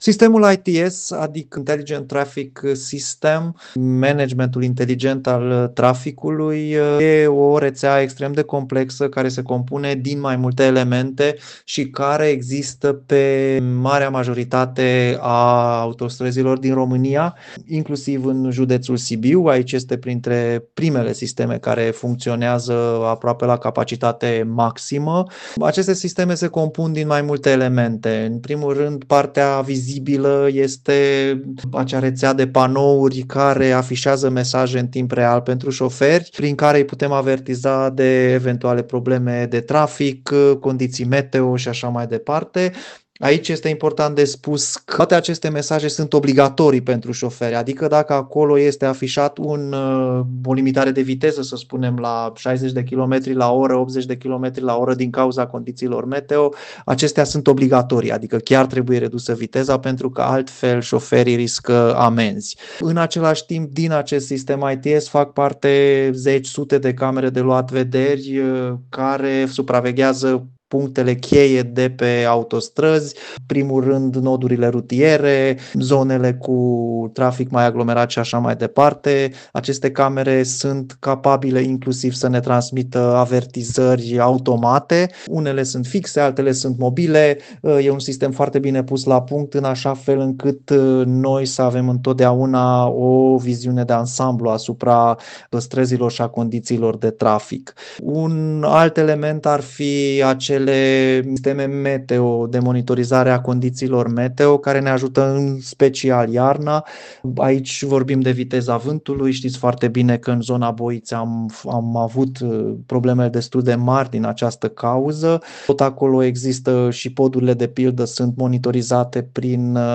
Un astfel de sistem funcționează pe porțiunile de autostradă din județul Sibiu, iar reprezentanții Secției Autostrăzi Sibiu au explicat rolul fiecărei componente din acest sistem.